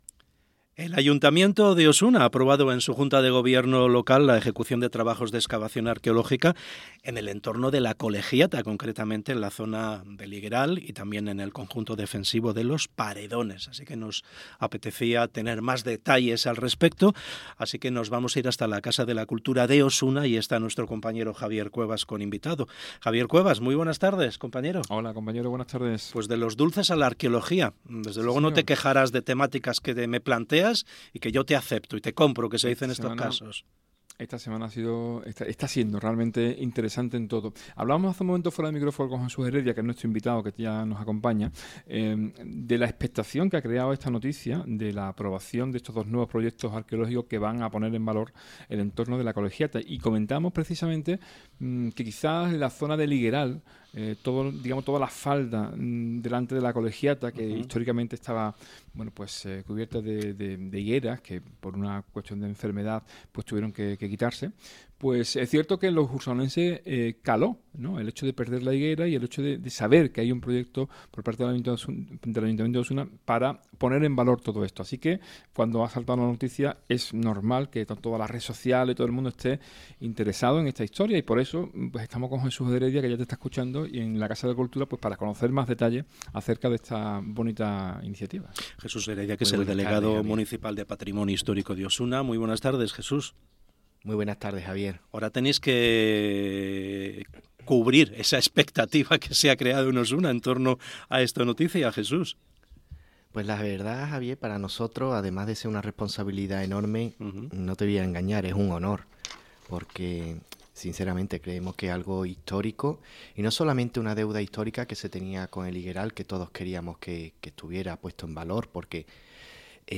Trabajos que tienen como objetivo identificar, localizar y registrar los vestigios materiales de las civilizaciones que habitaron estas zonas. Así lo ha explicado Jesús Heredia, delegado municipal de Patrimonio Histórico, en declaraciones a Hoy por Hoy SER Andalucía Centro.